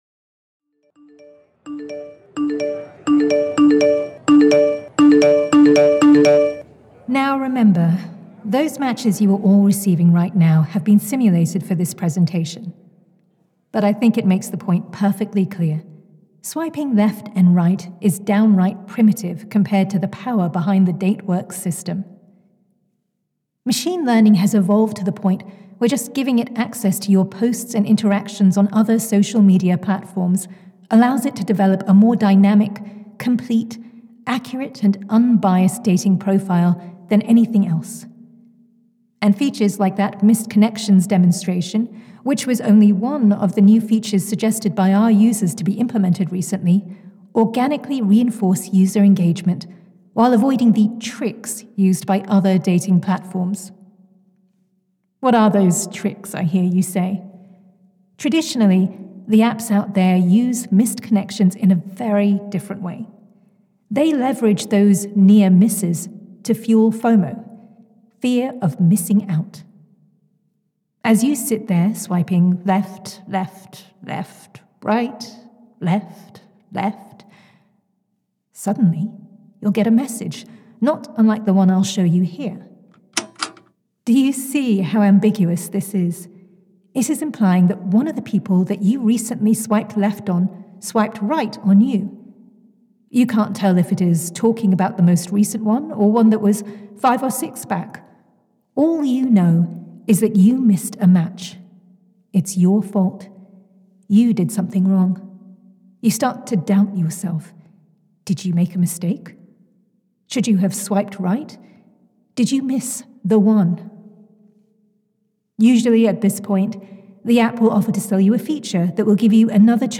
… continue reading 10 episodes # Tech # Society # Audio Drama # Spoken Realms